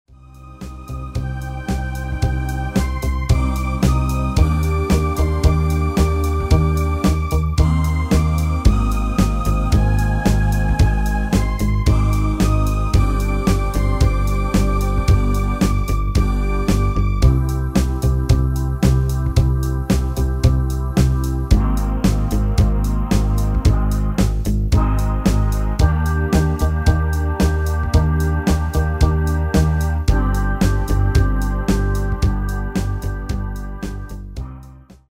Demo/Koop midifile
- GM = General Midi level 1
Demo's zijn eigen opnames van onze digitale arrangementen.